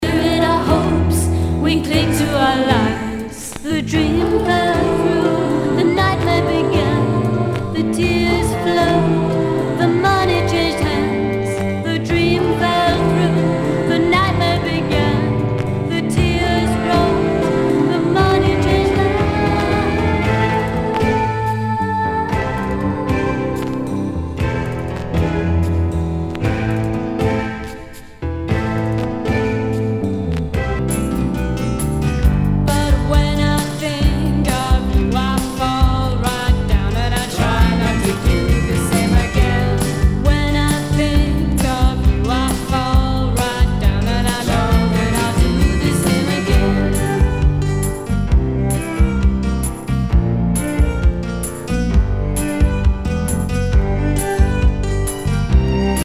HOUSE/TECHNO/ELECTRO
ナイス！ポップ・ロック！！
全体にチリノイズが入ります